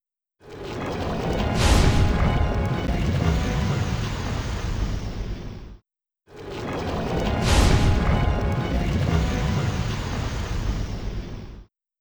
Help removing unwanted 'clicks'
I have some .mp4 gameplay videos that have random clicks? spikes? throughout them, that I’m trying to remove from the audio. I’ve attached a 5 second .wav file with an example at roughly 2 seconds. Sometimes the duration is slightly longer/louder.